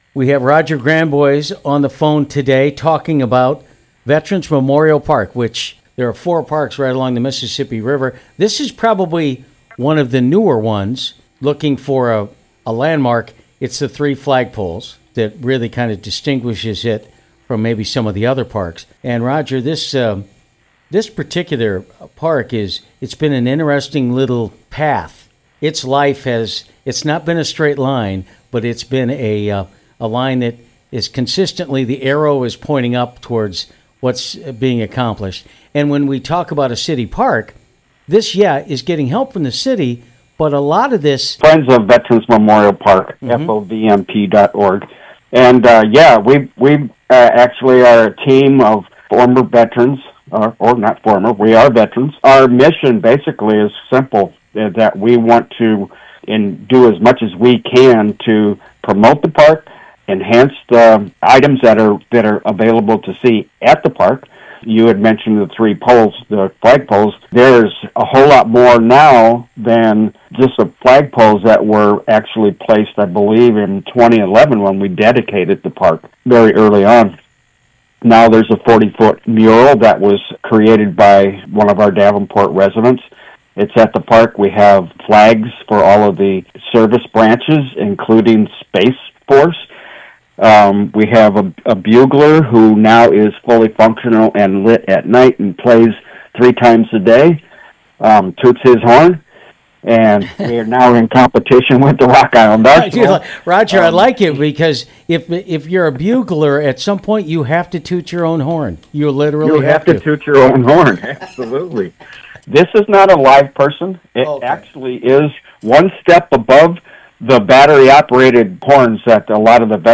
Commemorative Bricks Interview